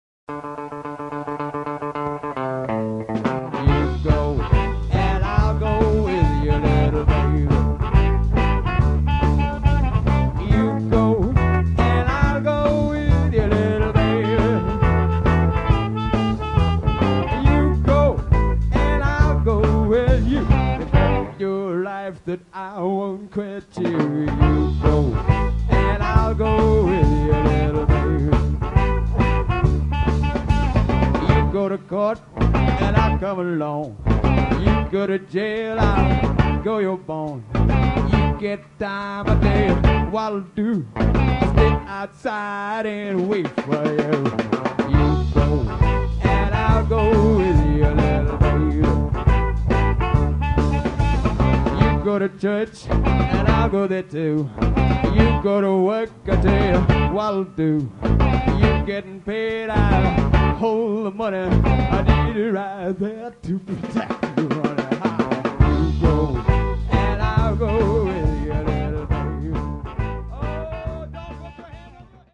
Classic live recordings from our vast back catalogue.